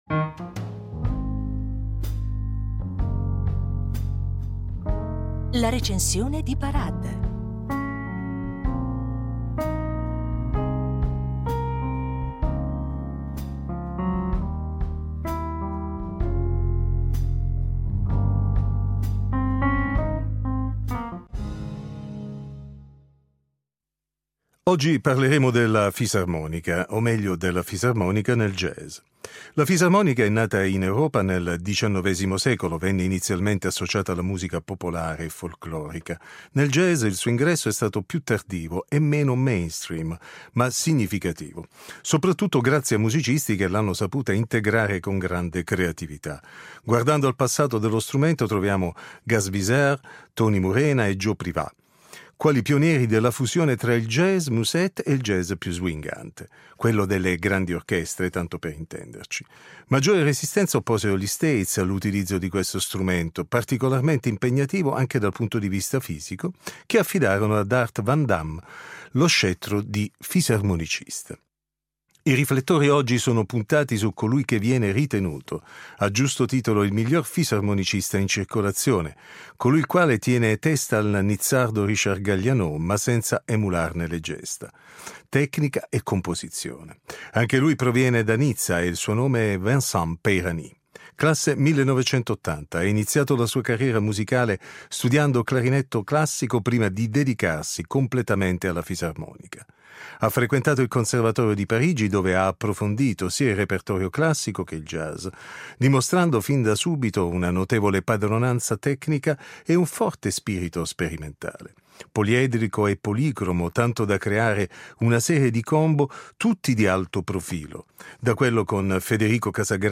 La Recensione
Lo ascoltiamo in anteprima per Rete 2 Radiotelevisione Svizzera Italiana.